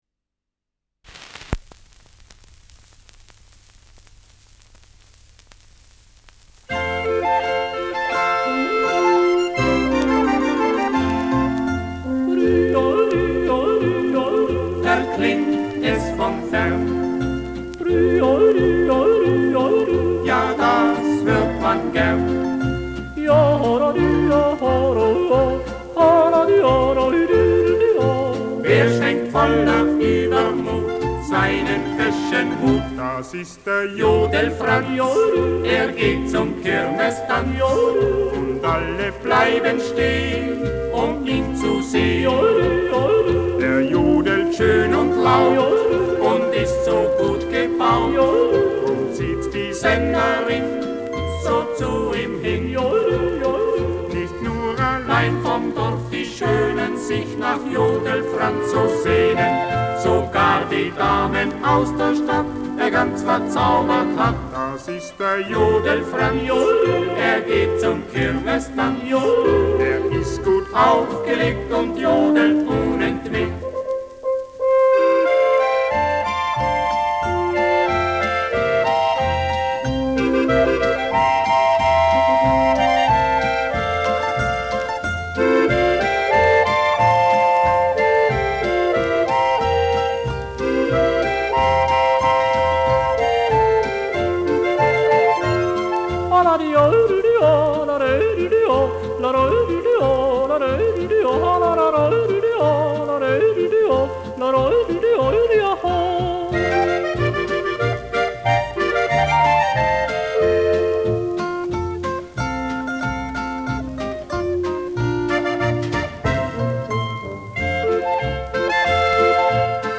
Schellackplatte
Leichtes Grundrauschen : Vereinzelt leichtes Knacken
Folkloristisches Ensemble* FVS-00015